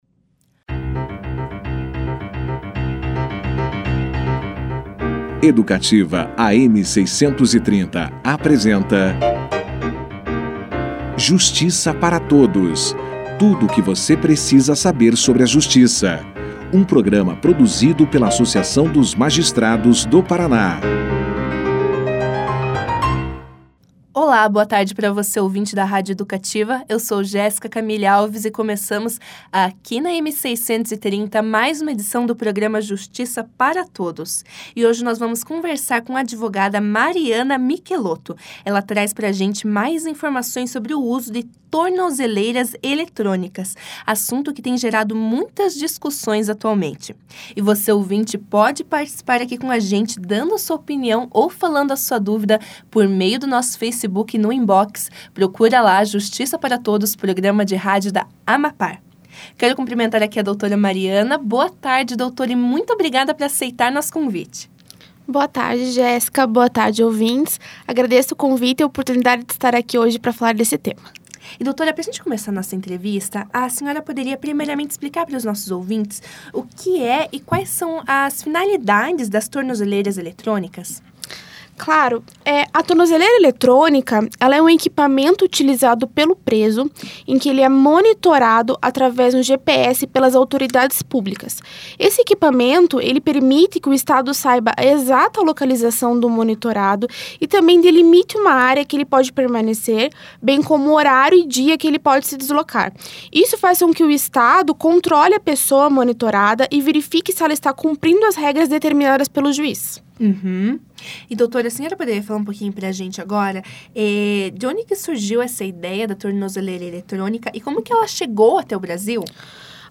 No programa da AMAPAR desta terça-feira (13), a juíza Nilce Regina Lima esclareceu aos ouvintes da rádio Educativa, AM 630, o que são execuções fiscais e falou também sobre seu trabalho a frente da 2ª Vara de Execuções Fiscais de Curitiba.
Mais ao final da entrevista, a juíza deu dicas aos ouvintes para evitarem um processo de execução fiscal.